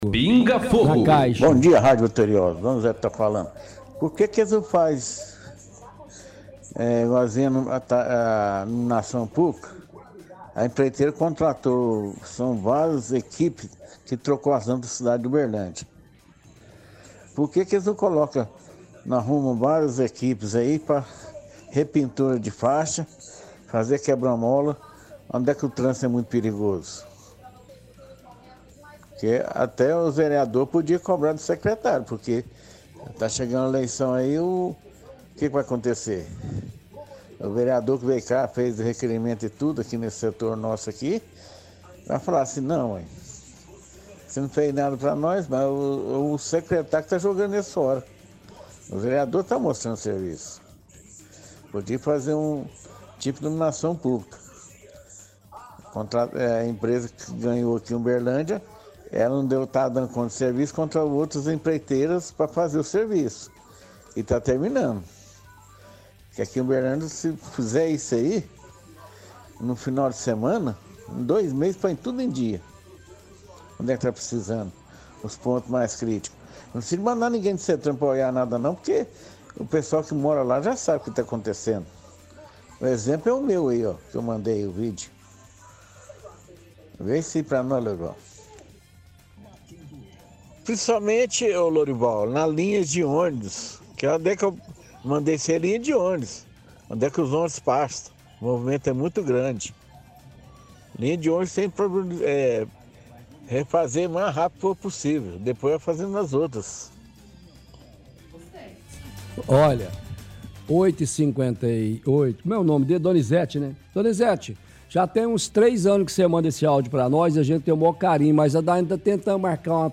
– Áudio antigo de ouvinte questionando a prefeitura do por que não contratam mais equipes para repintar faixas, colocarem mais quebra-molas, cobra dos vereadores. Diz que poderia contratar mais empresas pra “colocar tudo em dia”, fala que tem pontos críticos no trânsito da cidade. Ele também comenta que é de extrema urgência repintar faixas de ônibus.